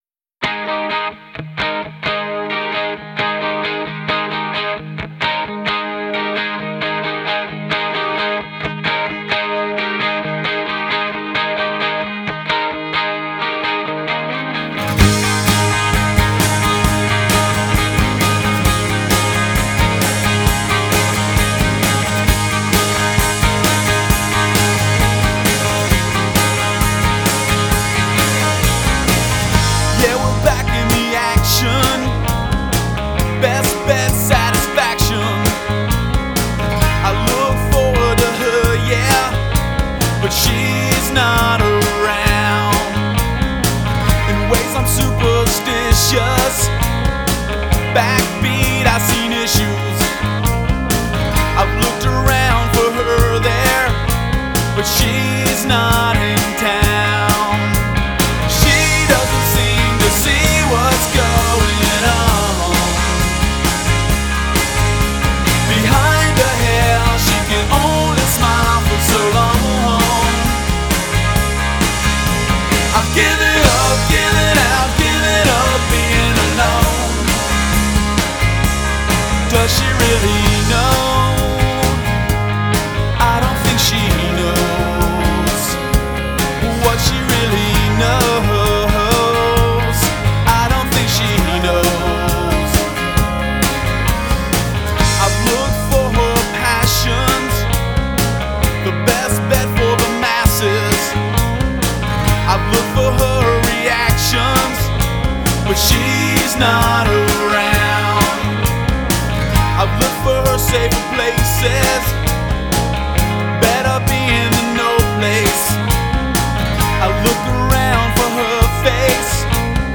languid, chorus pedal-drenched guitar opener